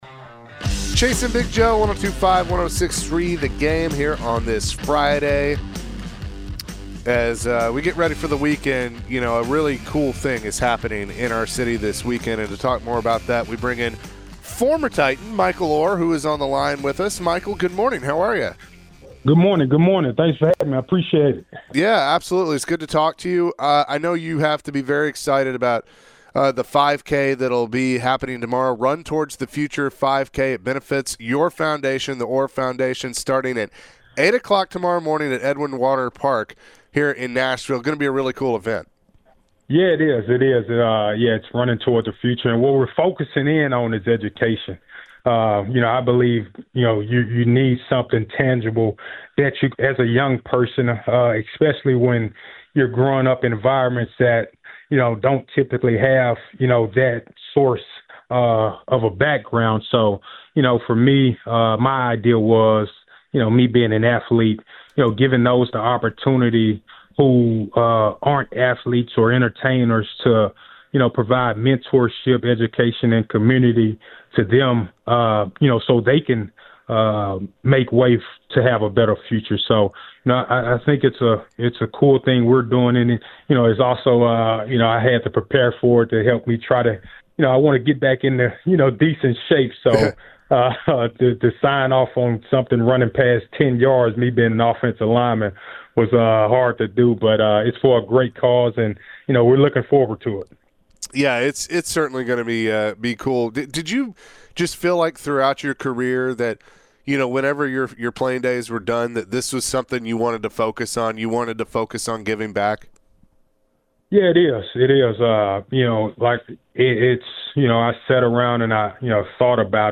Former NFL veteran and former Tennessee Titans offensive lineman Michael Oher joined the show and shared his upcoming 5K run tomorrow.